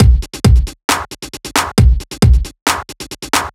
Clapper Break 135.wav